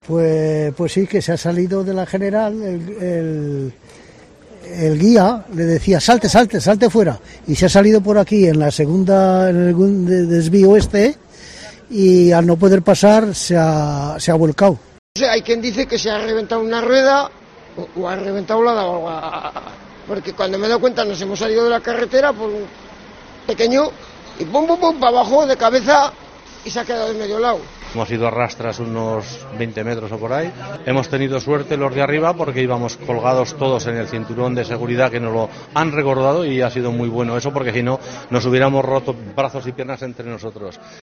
Testimonios de algunos de los pasajeros que viajaban en el autocar siniestrado en Mallorca